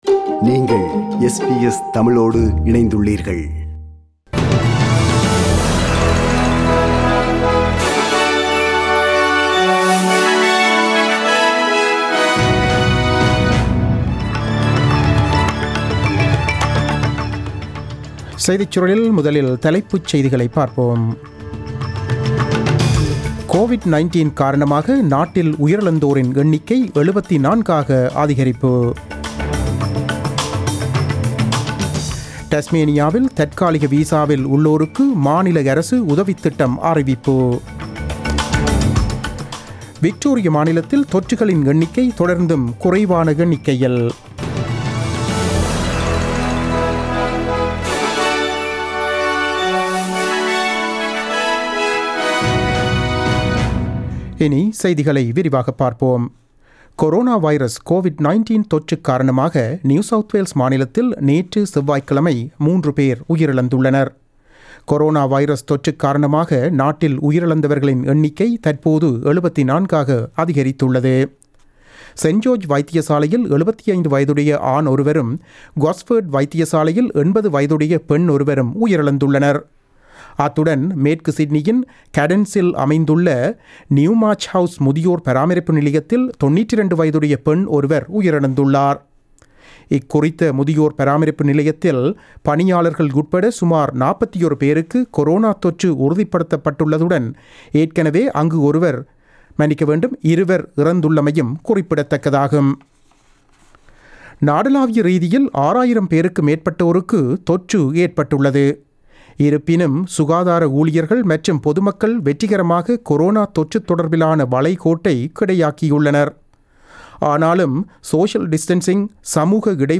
The news bulletin aired on 22 Apr 2020 at 8pm.